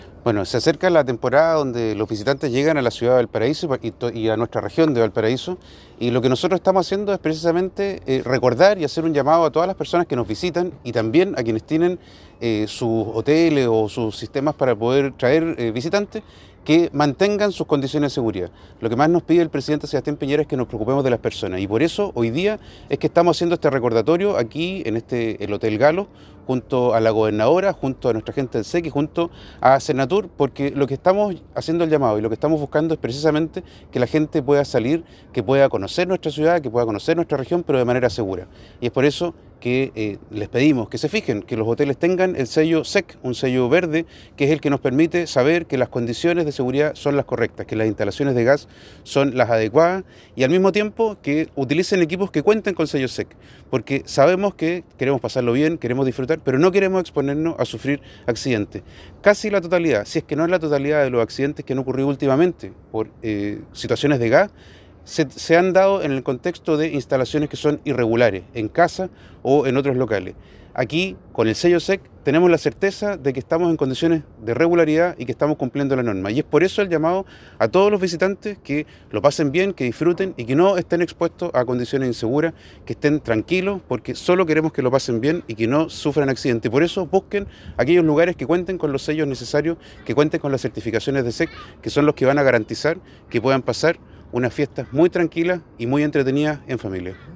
Seremi-de-Energía.mp3